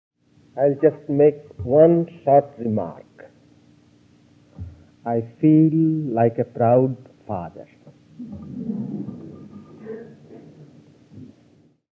[20] Sound Clip, Swami Prabhavananda’s entire closing remarks to Chris’ Hollywood Temple lecture The Writer & Vedanta, sound clip link here: